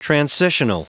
Prononciation du mot transitional en anglais (fichier audio)